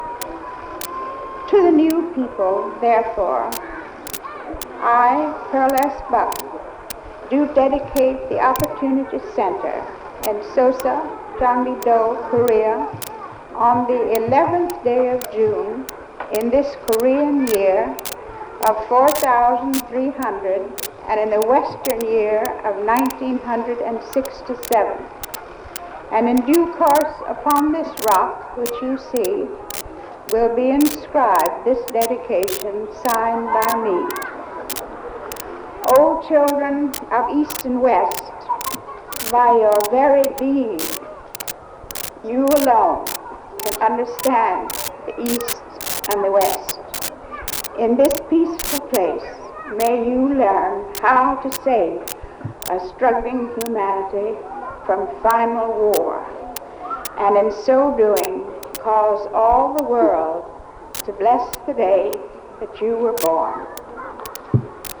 Pearl S. Buck gave a speech at the opening of the Opportunity Center in 1967.
PSB-Opp-Center-Dedication-Excerpt.mp3